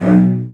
CELLOS.C#2.1.wav